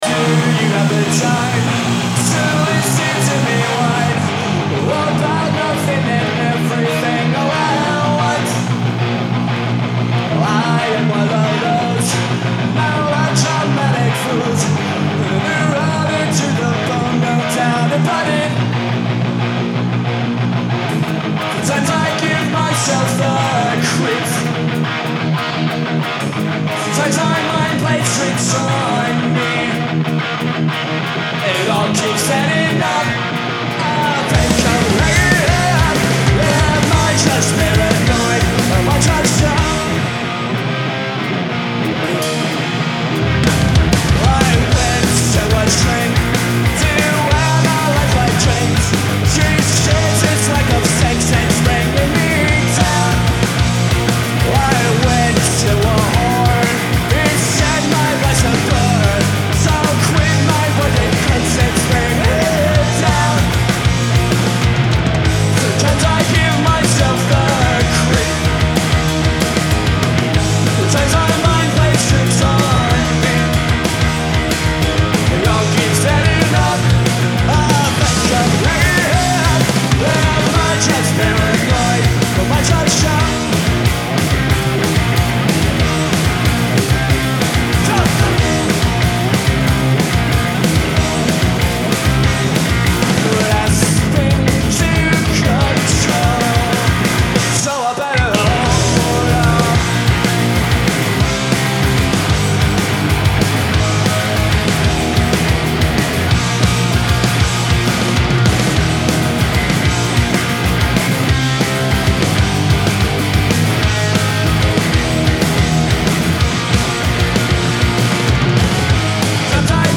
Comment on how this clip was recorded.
Live at Woodstock 1994